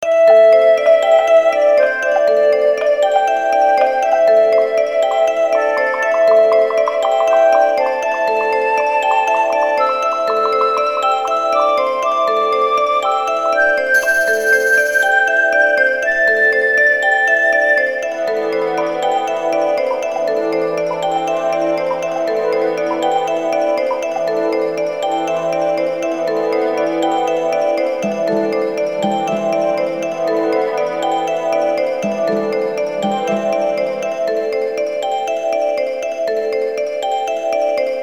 【746KB　0:38】イメージ：不思議、静か　※ループ素材